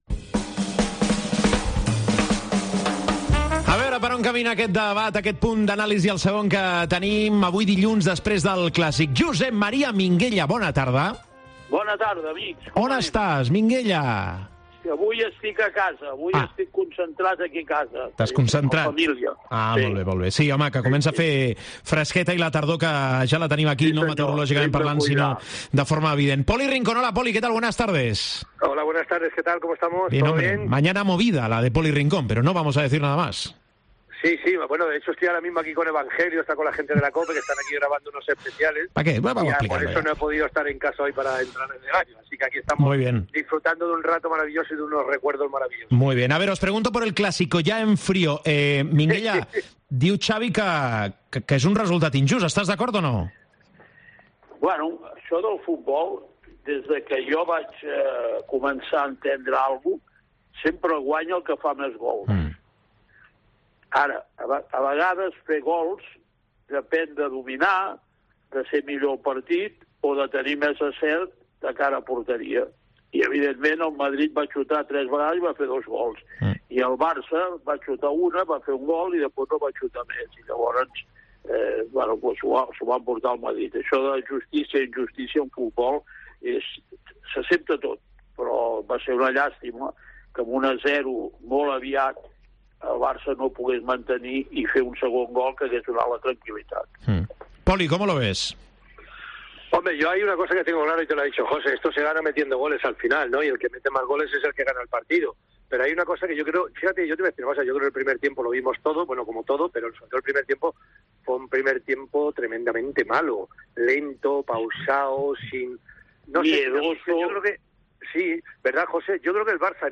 AUDIO: Els dos col·laboradors de la Cadena COPE repassen l'actualitat esportiva de la setmana.